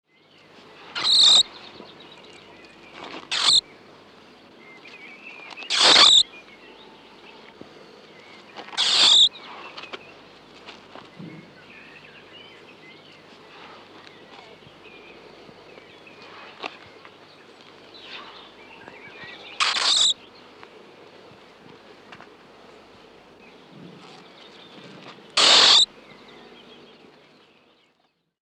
звук крота